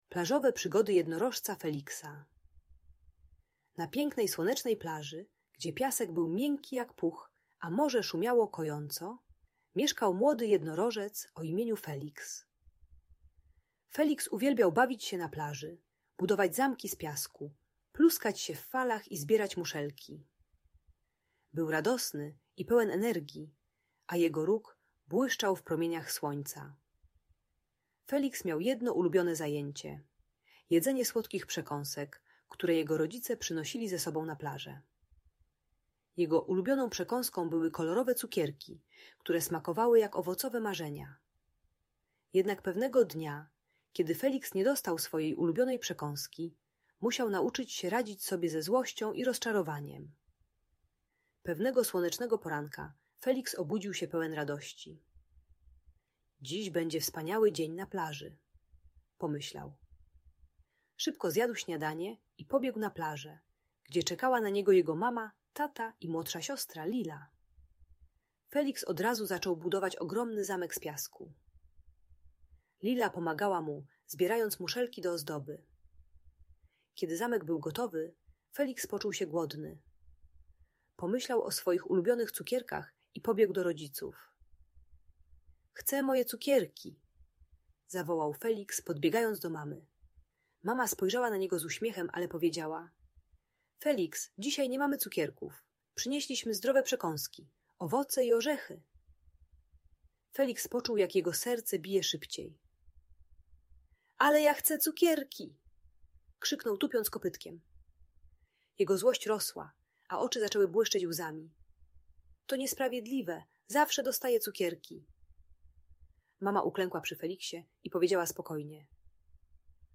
Plażowe Przygody Jednorożca Feliksa - Audiobajka